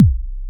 edm-kick-59.wav